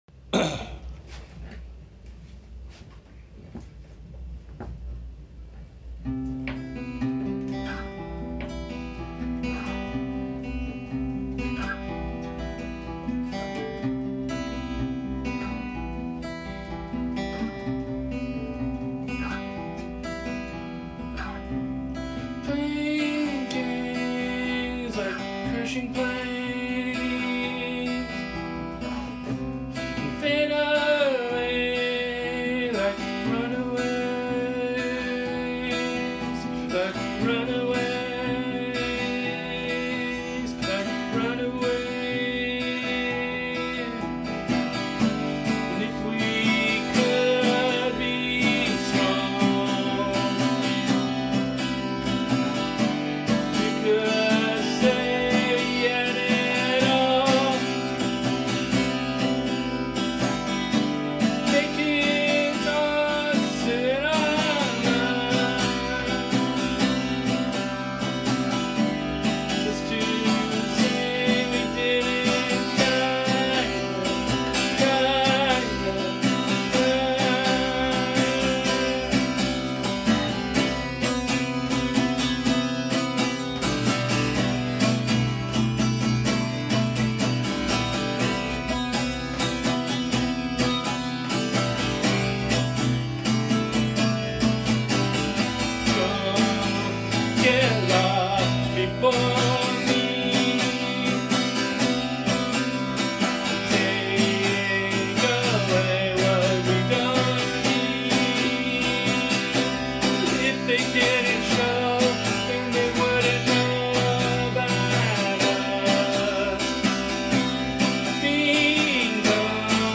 Here's a work in progress track.
A four piece indie rock band from the Bay Area, California.
rhythm guitar
lead guitar
bass
drums